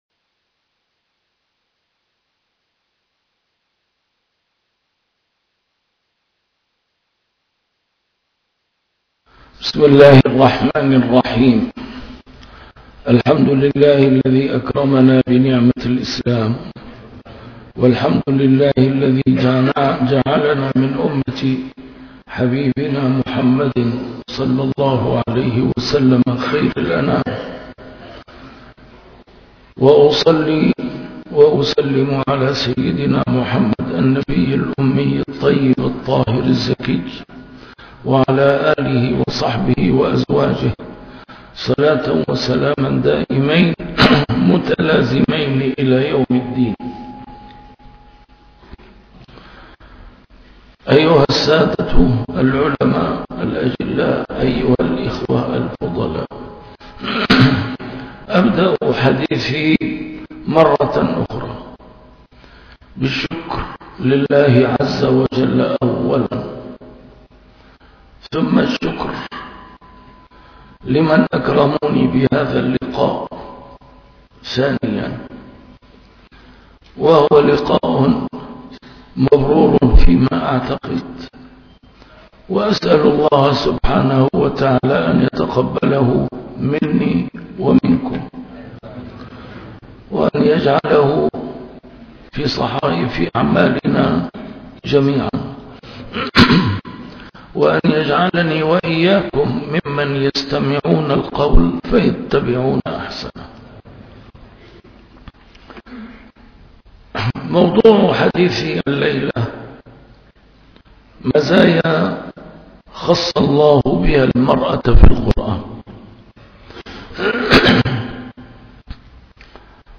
A MARTYR SCHOLAR: IMAM MUHAMMAD SAEED RAMADAN AL-BOUTI - الدروس العلمية - محاضرات متفرقة في مناسبات مختلفة - محاضرة بعنوان: مزايا اختص الله بها المرأة في القرآن